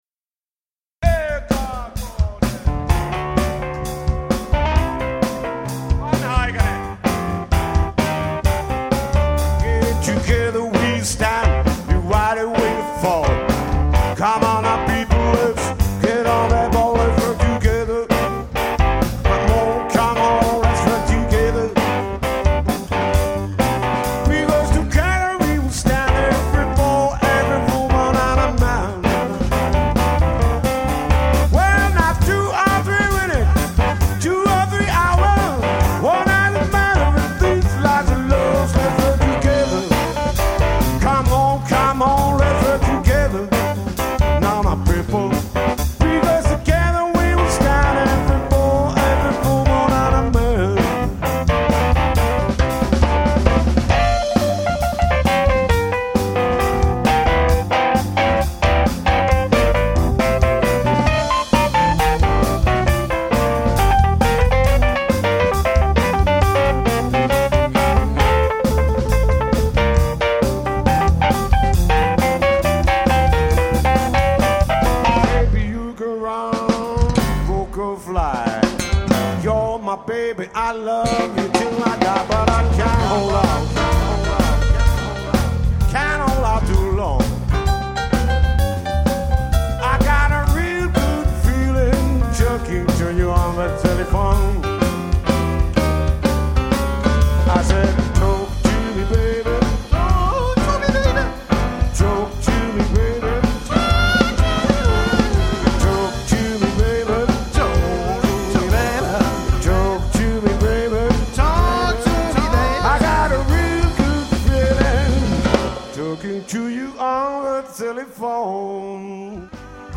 kosketinsoitin & laulu
kitara tai basso & laulu
rummut